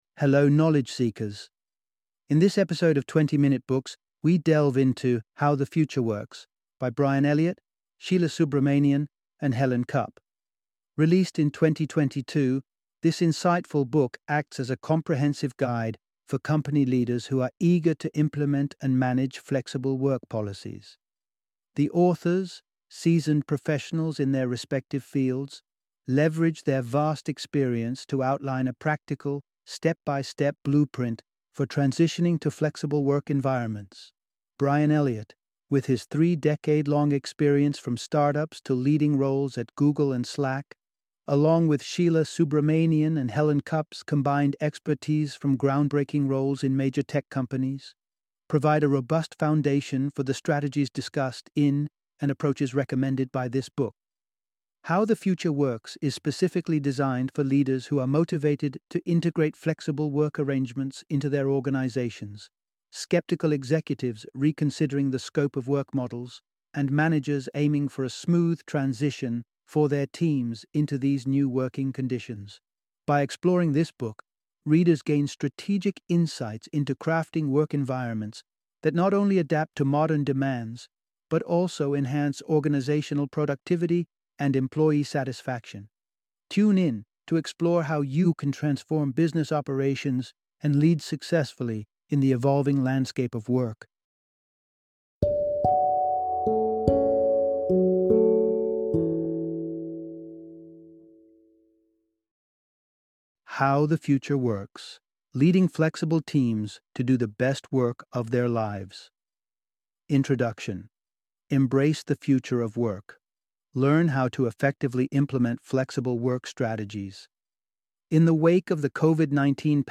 How the Future Works - Audiobook Summary